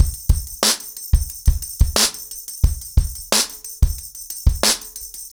ROOTS-90BPM.11.wav